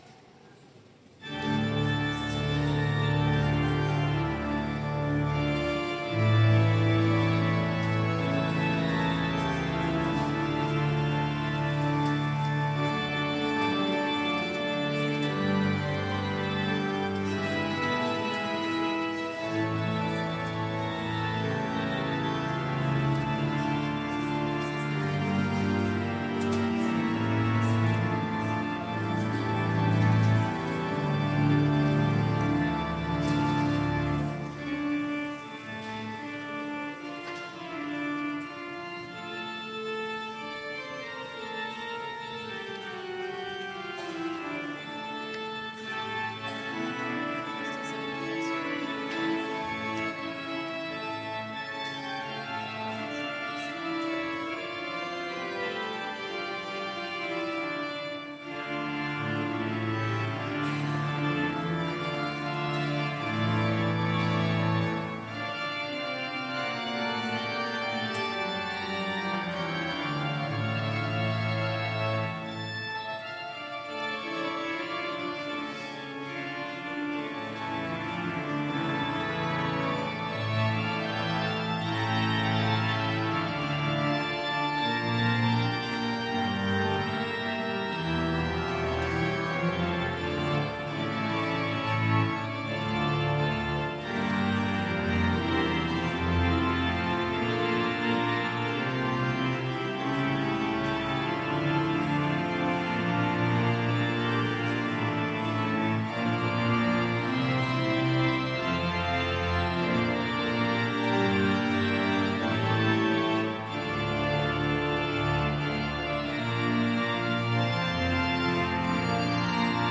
Culte JMP 2023 - Oratoire du Louvre